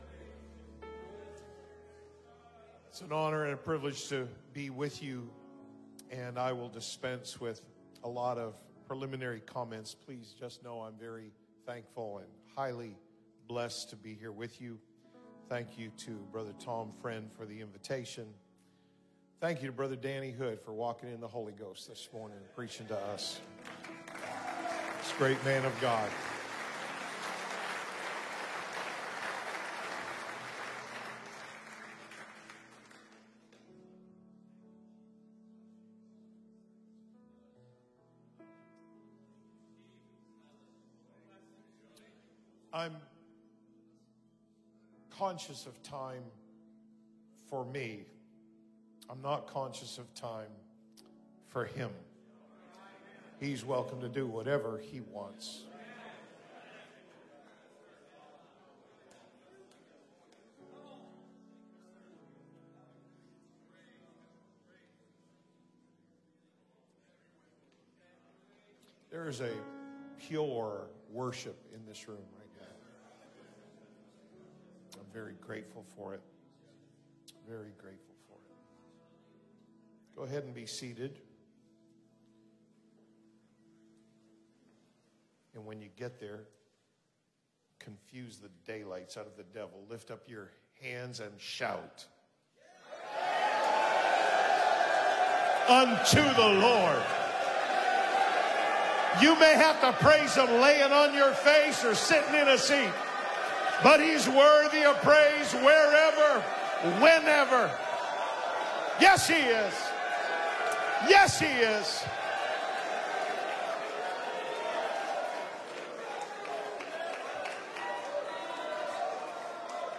Men’s Conference 2025